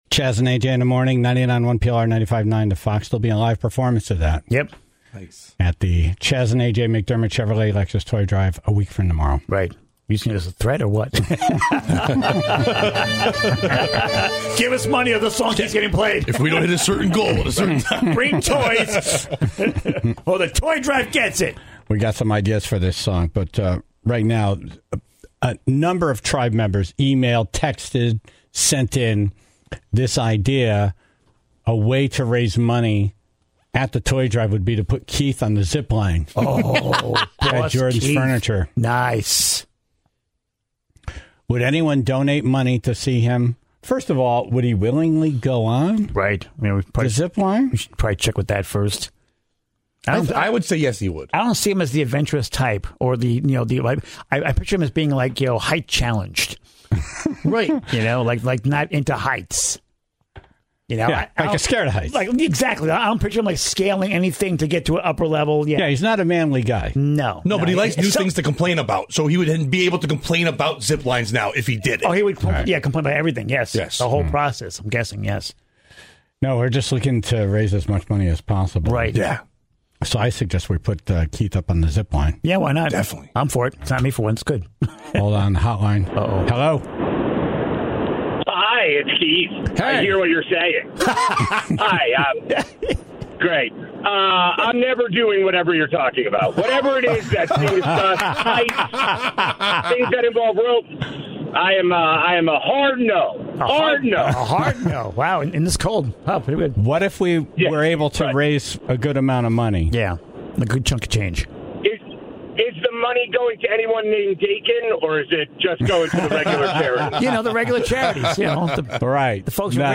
(18:24) In Dumb Ass News, a 12-year-old was arrested for stealing a forklift, leading police on an hour-long chase, and smashing up some cars in the process. The Tribe called in to share their stories of breaking the law at an early age.